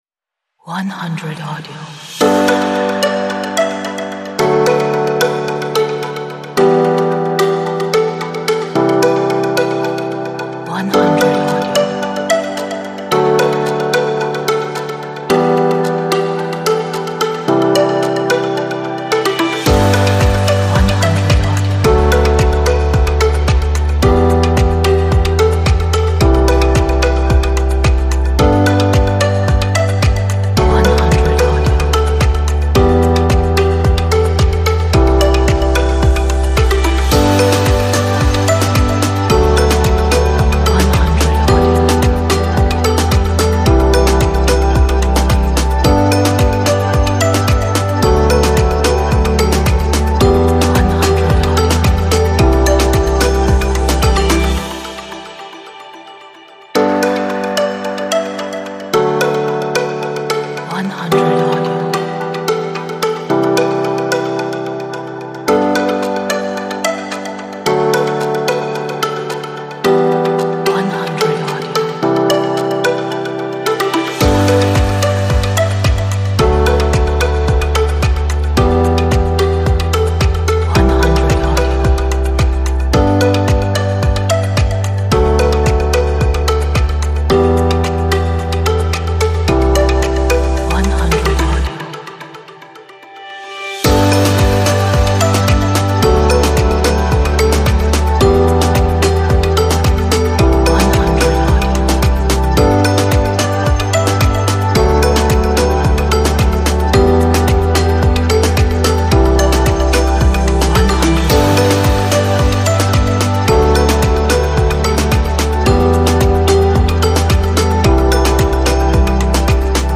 Uplifting track for your projects. 为您的项目专门准备的振奋人心的音乐。